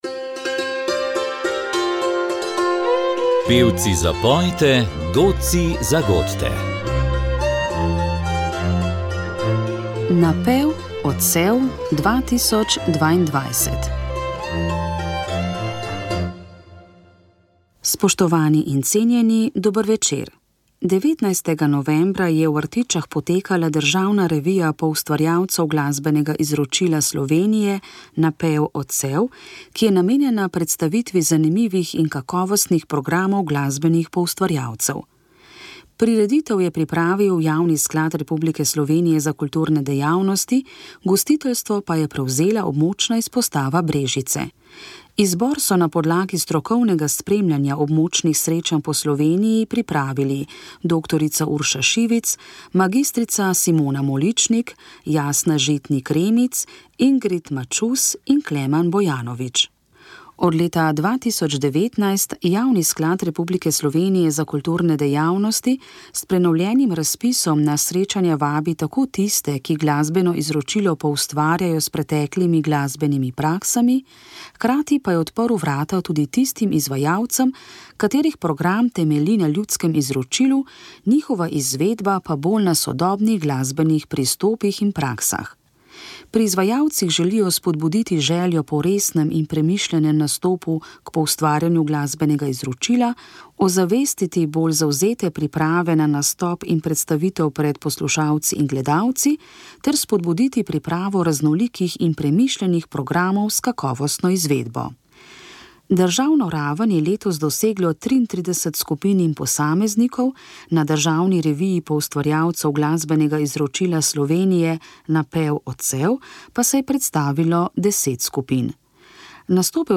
19. novembra je v Artičah potekala državna revija poustvarjalcev glasbenega izročila Slovenije Napev – odsev, ki je namenjena predstavitvi zanimivih in kakovostnih programov glasbenih poustvarjalcev.
Prvi del srečanja, na katerem je nastopilo 10 skupin, smo predvajali v tokratni oddaji iz cikla Pevci zapojte, godci zagodte.